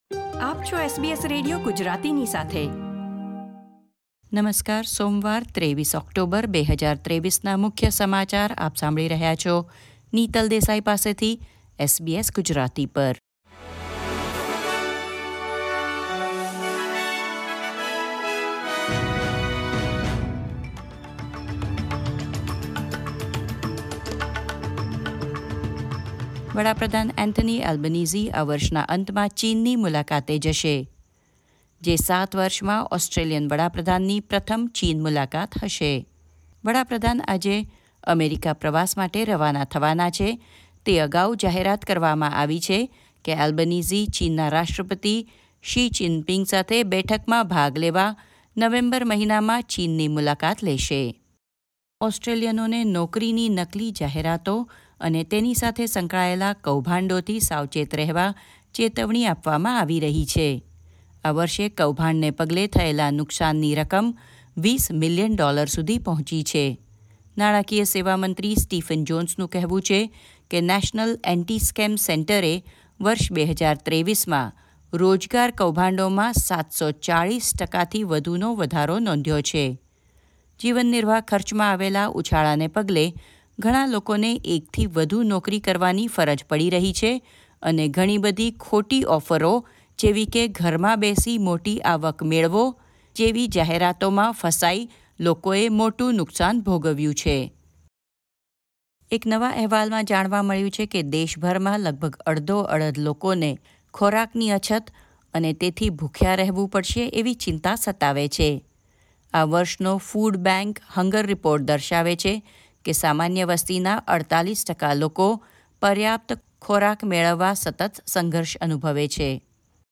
SBS Gujarati News Bulletin 23 October 2023